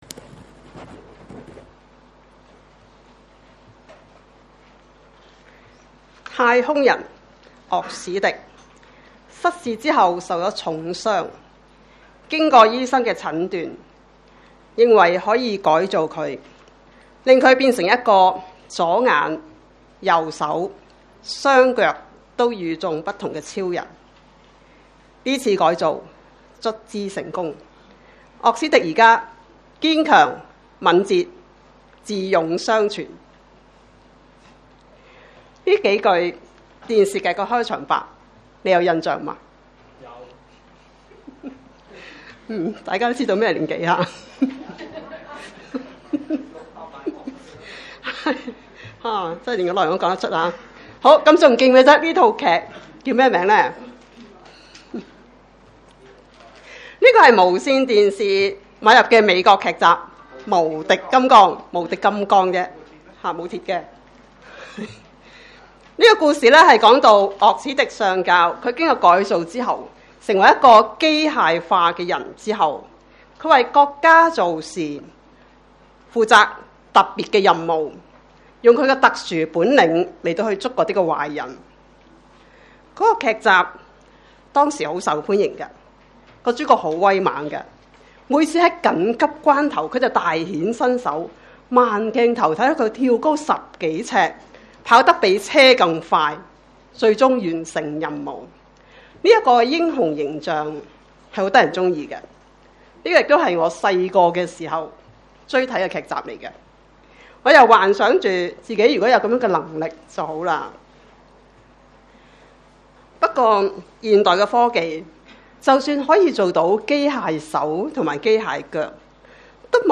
香港基督教循道衛理聯合教會: 講道重溫